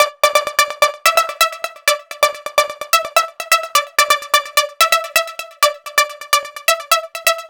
Retro Synth.wav